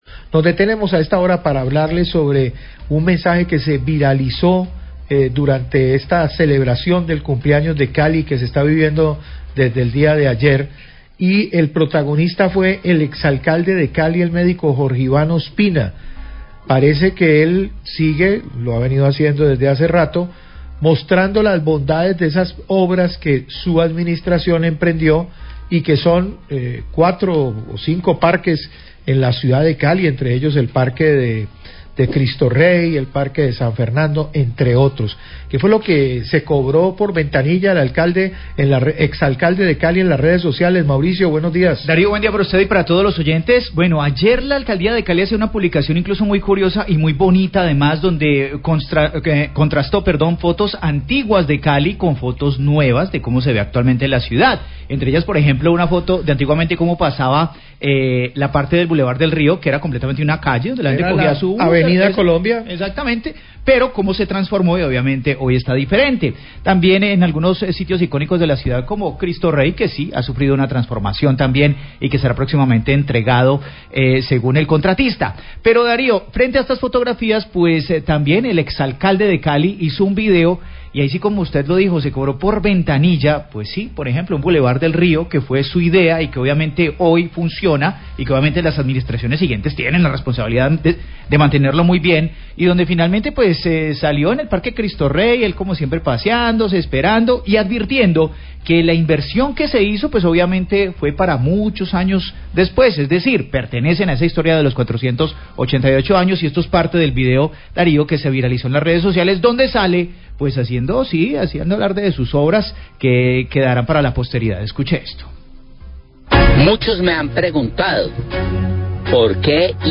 Radio
mensaje whatsapp
Mensaje por whatsapp del exalcalde Jorge Ivan Ospina donde "cobra por ventanilla" las obras del Parque de Cristo Rey que están próximas a entregarse. Periodistas hablan de la visita de la Procuraduría a las obras del Ecoparque Corazón de Pance y constató su avance.